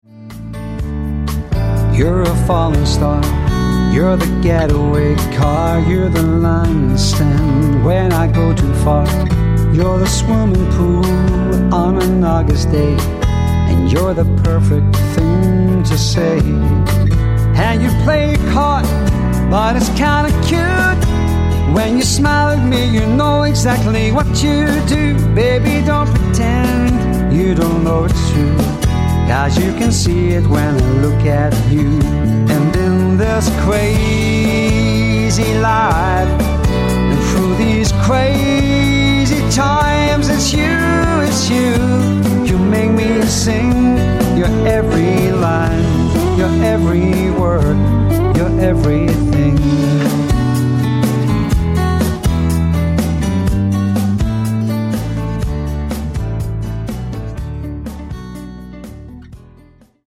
swing
Tribute Music Samples